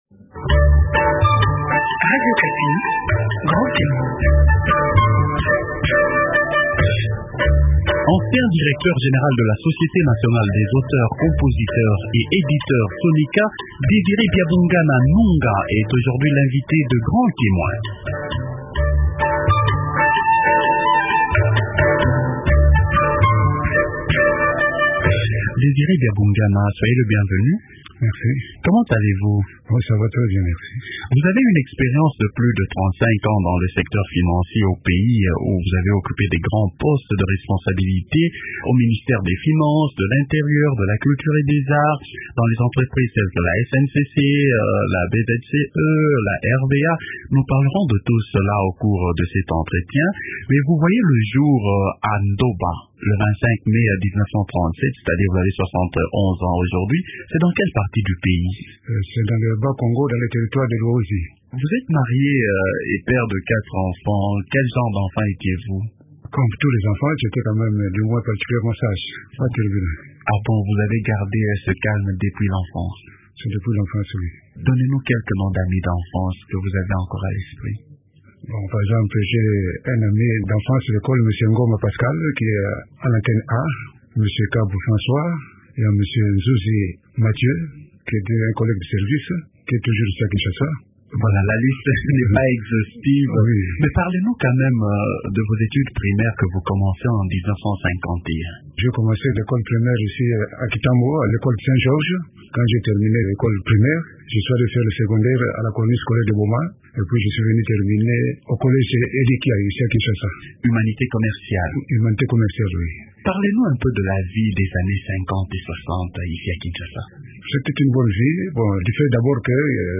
Dans son entretien